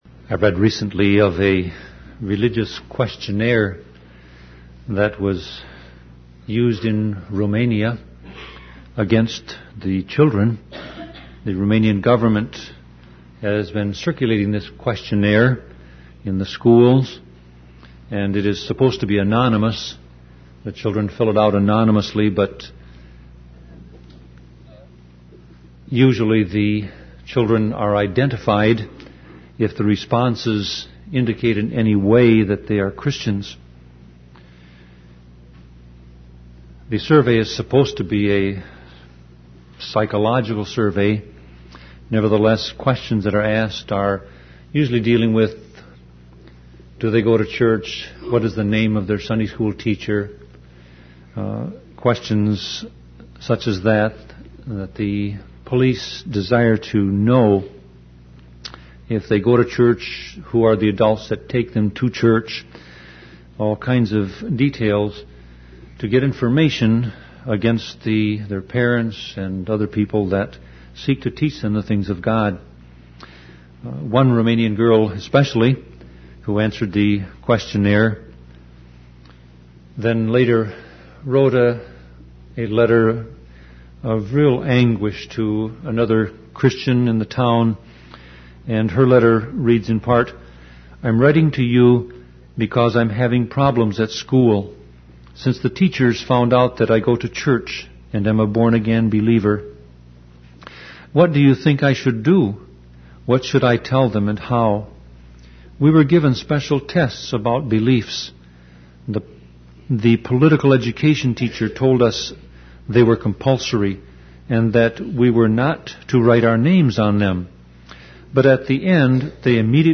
Sermon Audio Passage: 1 Peter 4:12-13 Service Type